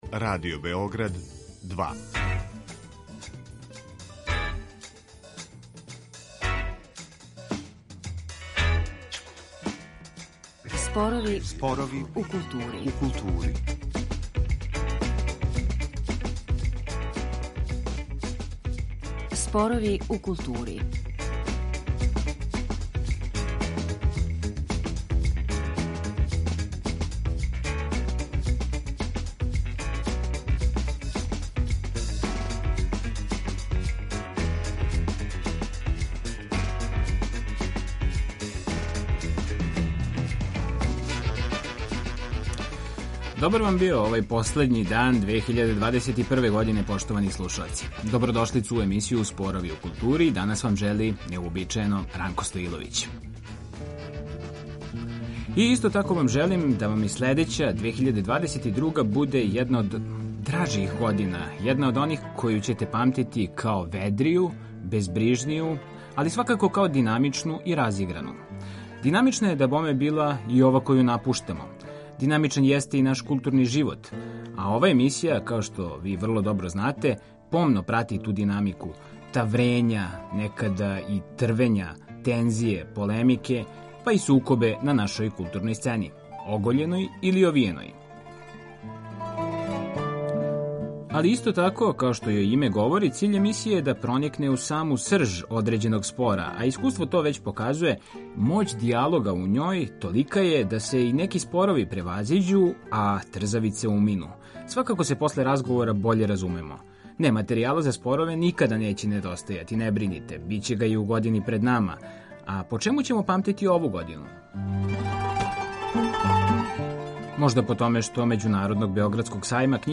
Дијалошка емисија која сагледава културу као непрестано кретање, које се остварује кроз немир, тензије и сударе различитих становишта, током године за нама била је, као и увек, верно огледало тих трвења, несугласица и полемика.